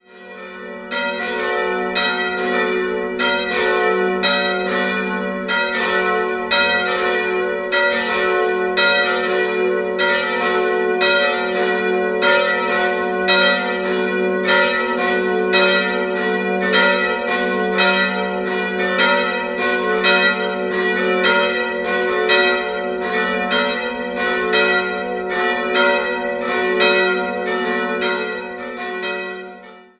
Nachdem die Katholiken für kurze Zeit in einer eigenen Kapelle ihre Gottesdienste feiern konnten, wurde ab 1913 eine neue, große Kirche errichtet. 1916 fand die Weihe statt. Im Jahr 1953 kam es im Rahmen einer Renovierung zu einer größeren Umgestaltung des Innenraums. 3-stimmiges Geläut: fis'-a'-h' Die Eisenhartgussglocken werden per Hand geläutet und wurden 1947 von Schilling&Lattermann gegossen.